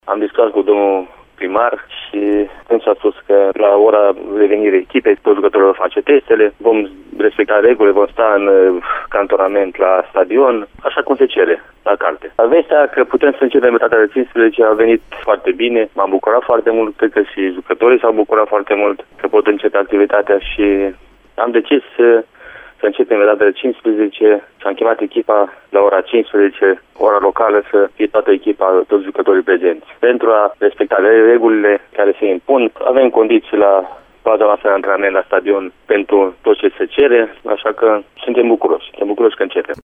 Antrenorul rosso-nerilor spune că, din discuțiile sale cu primarul Ioan Popa, în Valea Domanului sunt toate condițiile pentru a respecta criteriile impuse de Ministerul Tineretului și Sportului, care prevăd cantonarea jucătorilor, a staff-ului și a personalului adiacent: